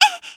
文件 文件历史 文件用途 全域文件用途 Chorong_dmg_02.ogg （Ogg Vorbis声音文件，长度0.4秒，178 kbps，文件大小：8 KB） 源地址:地下城与勇士游戏语音 文件历史 点击某个日期/时间查看对应时刻的文件。